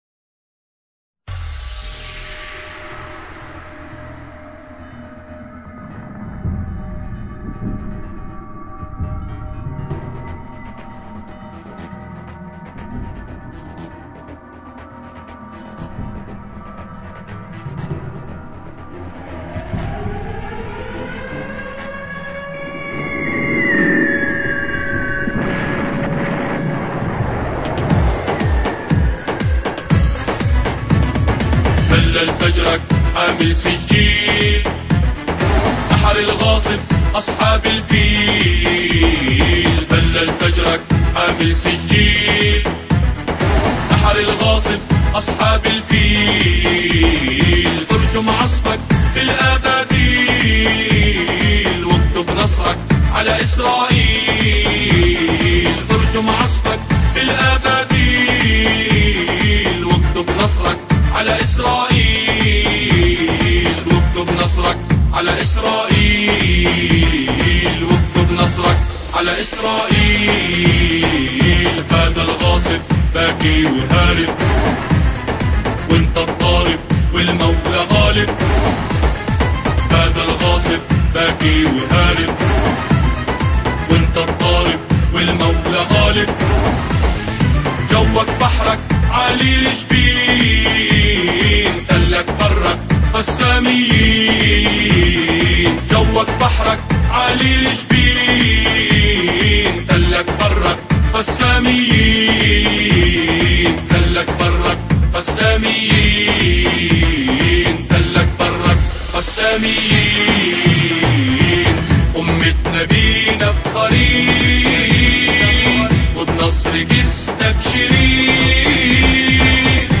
أناشيد فلسطينية... وفى القسام